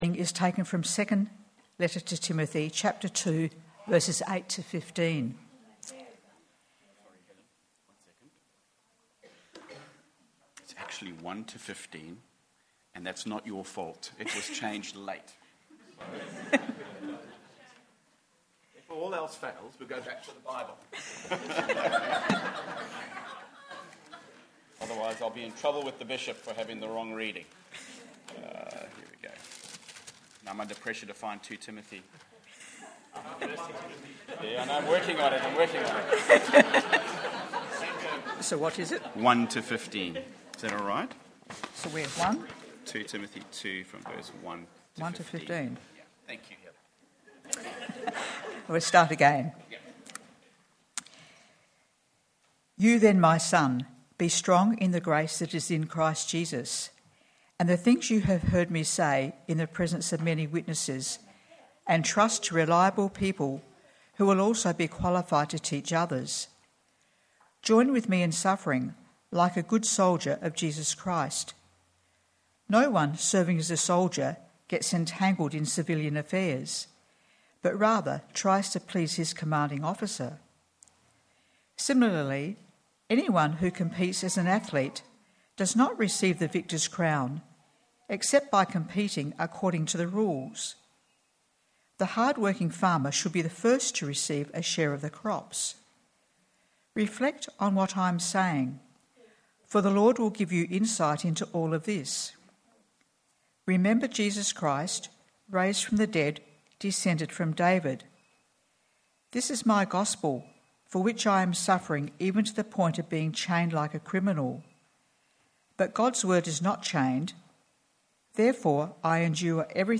Sermons | Living Water Anglican Church
Confirmation Service